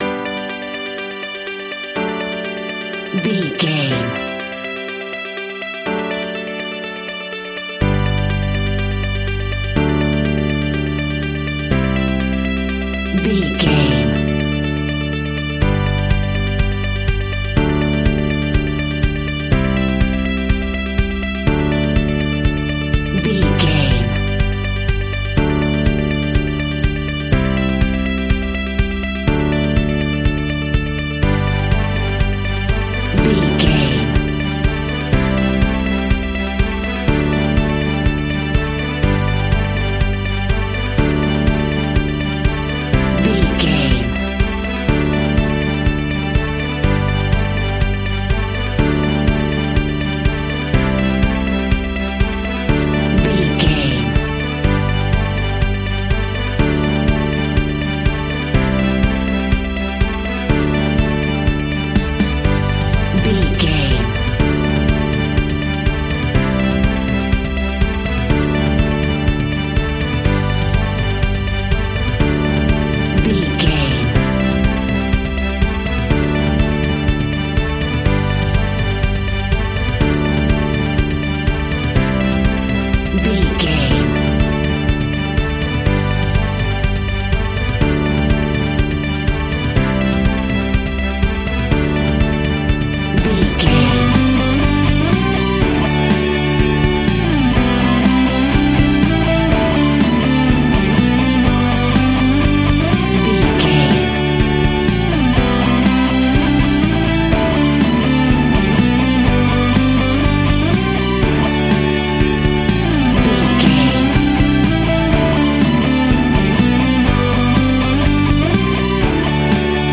Ionian/Major
pop rock
indie pop
energetic
uplifting
instrumentals
upbeat
groovy
guitars
bass
drums
piano
organ